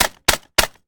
hammer.ogg